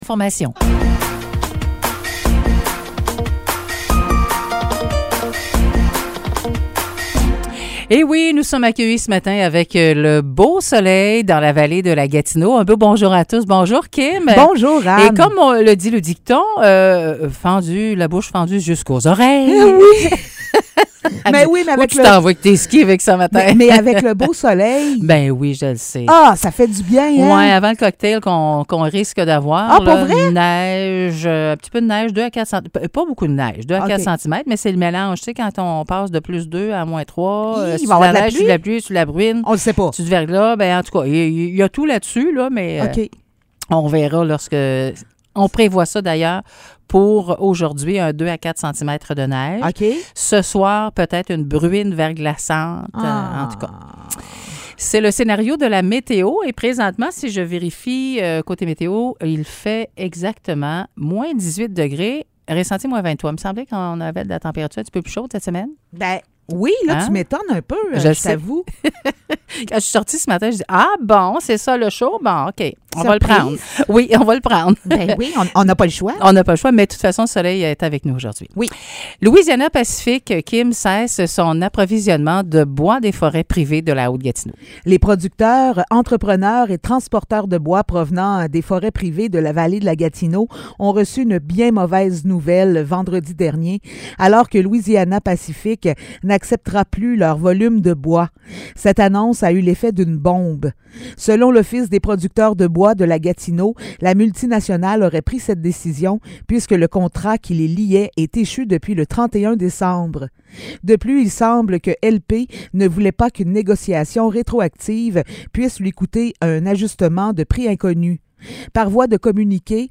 Nouvelles locales - 7 février 2023 - 9 h